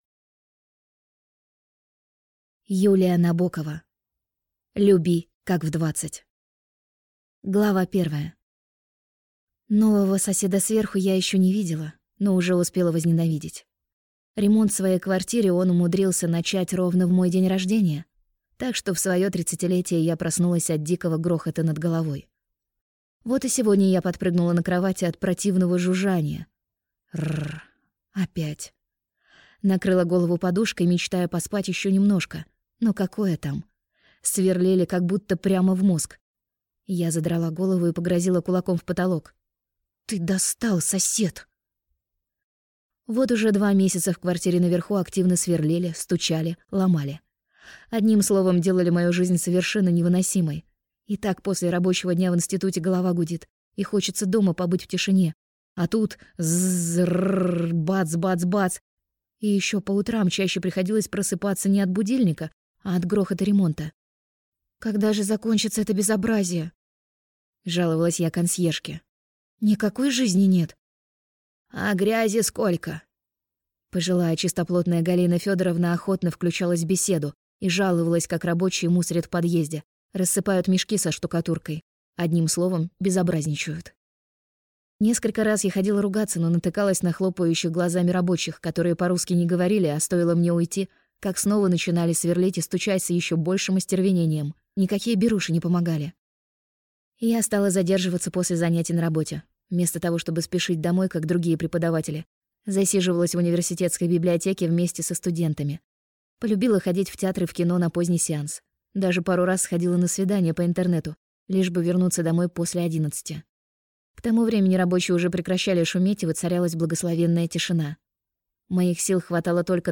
Аудиокнига Люби как в 20!